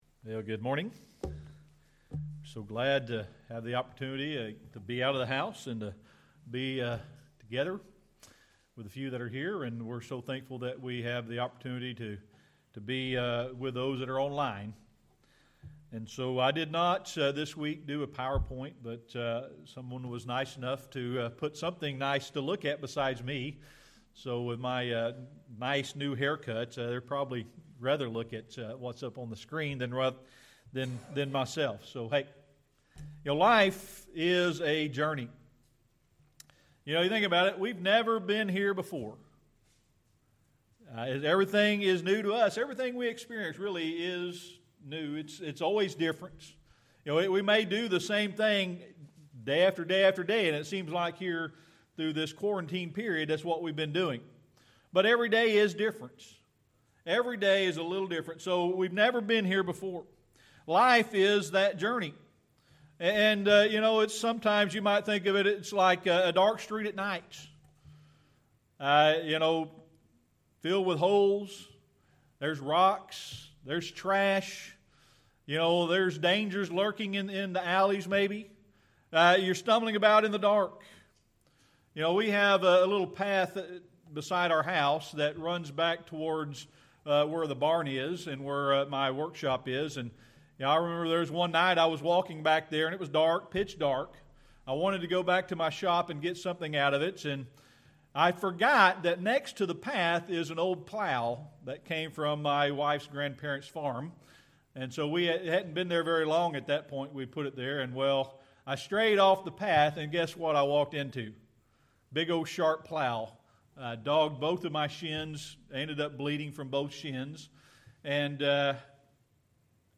Psalm 119:105 Service Type: Sunday Morning Worship A Lamp and a Light